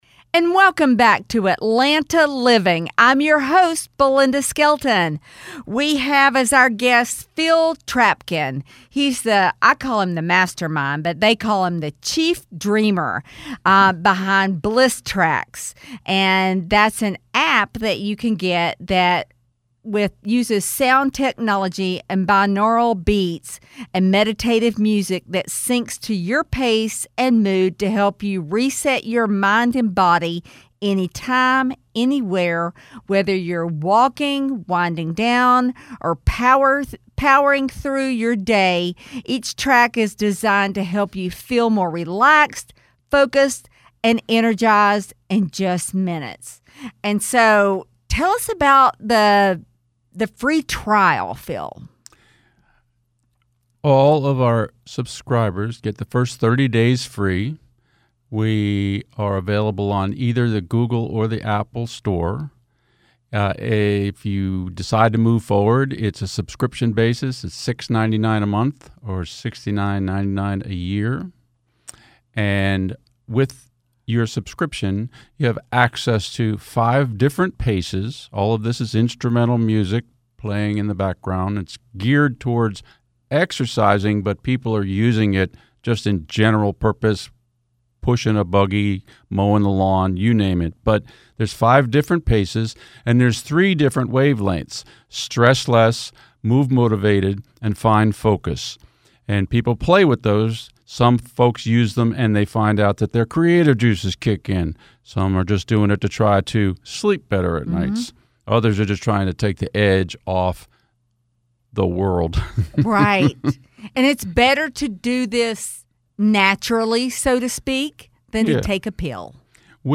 The conversation dives into how the app fits seamlessly into busy schedules—whether that’s during work, commuting, or winding down at home.